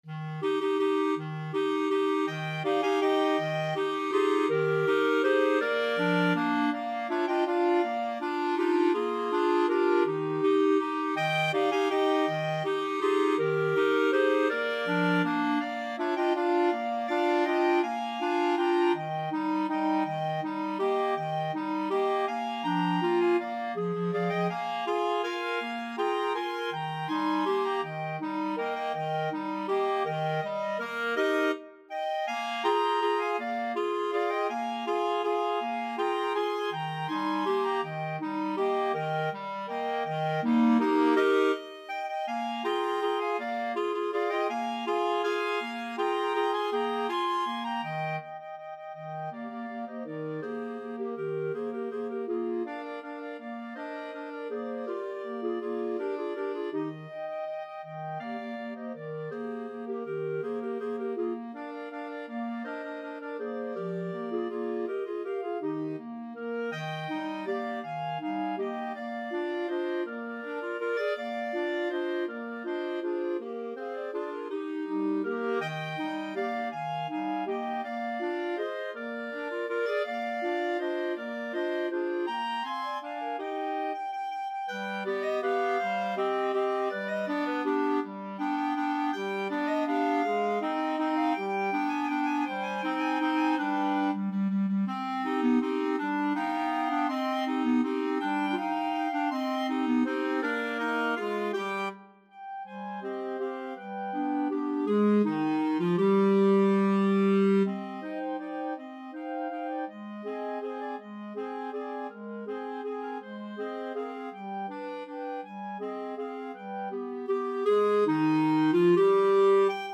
Free Sheet music for Clarinet Quartet
Eb major (Sounding Pitch) F major (Clarinet in Bb) (View more Eb major Music for Clarinet Quartet )
3/4 (View more 3/4 Music)
Lively one in a bar . = c. 54
Classical (View more Classical Clarinet Quartet Music)